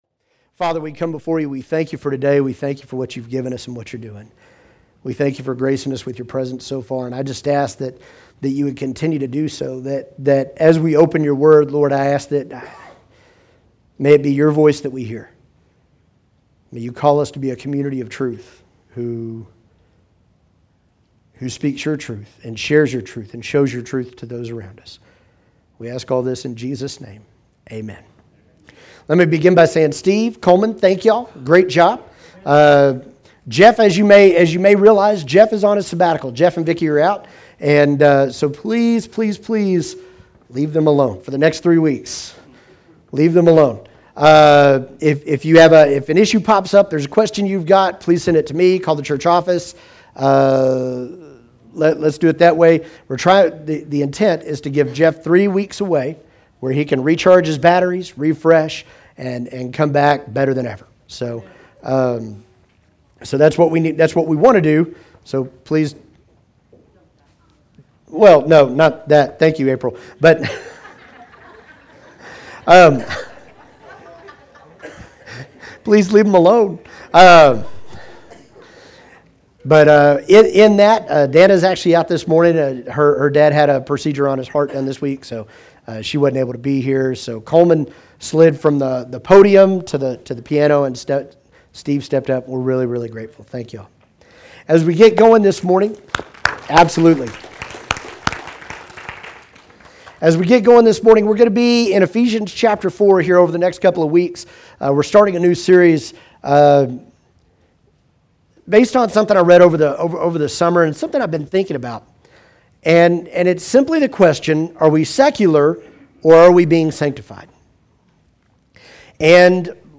Chapel Hill Baptist Church Online Sermons